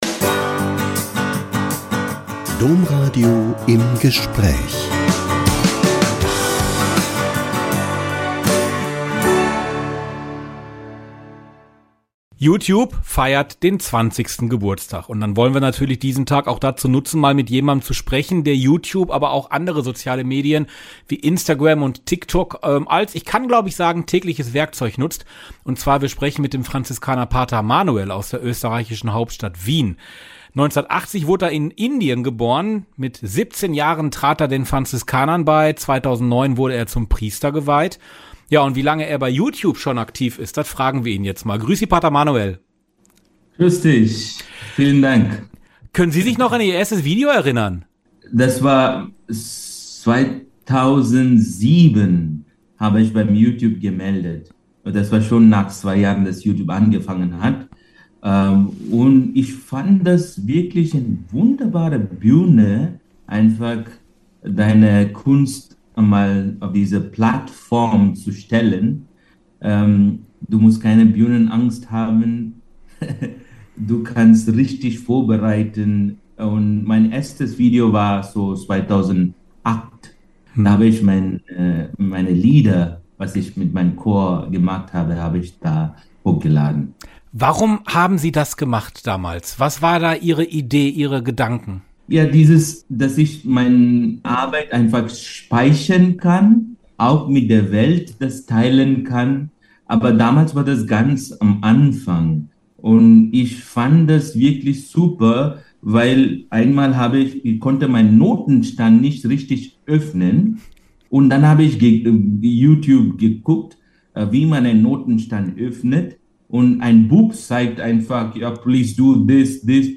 Franziskanerpater erläutert Einsatz digitaler Medien in der Seelsorge